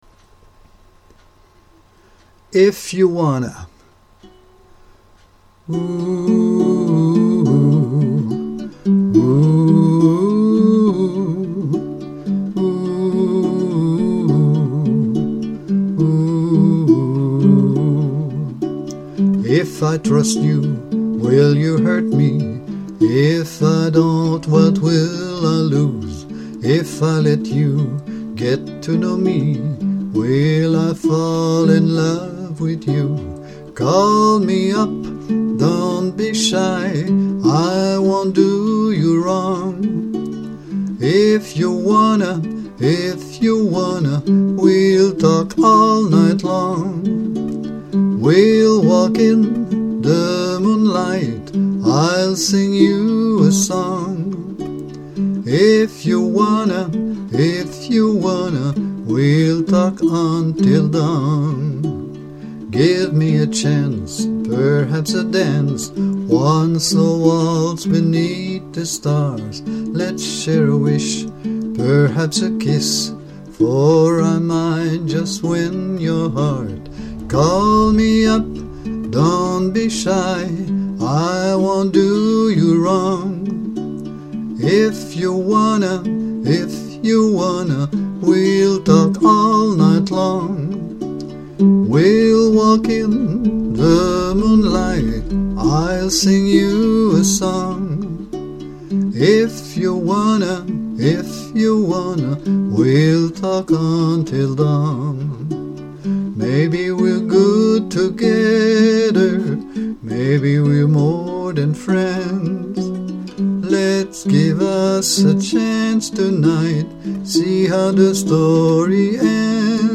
Intro: 4 bars (key of D, 6/8) tempo:96chords: I=D, 2m=Em, V=A, 3m=f#m, 6m=Bm